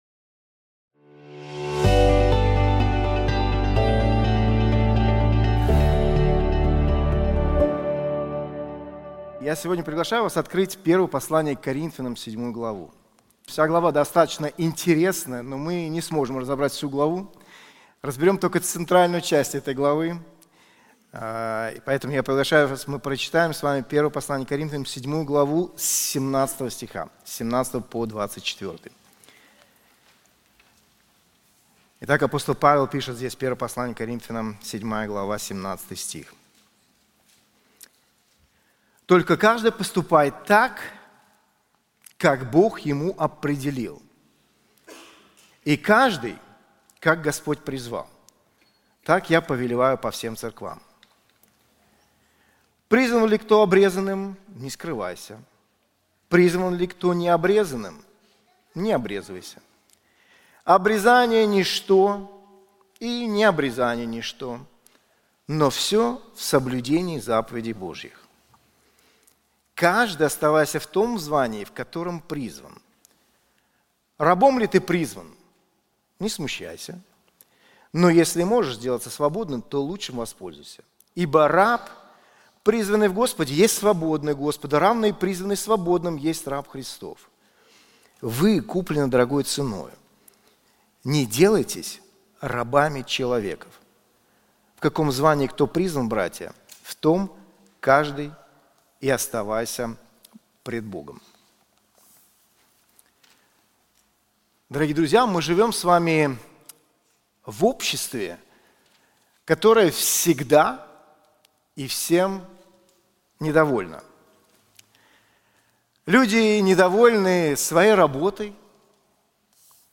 Проповеди